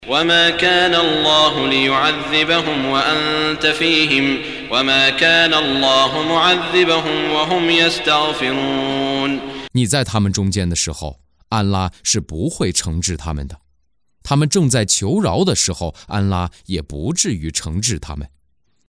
中文语音诵读的《古兰经》第（安法里）章经文译解（按节分段），并附有诵经家沙特·舒拉伊姆的朗诵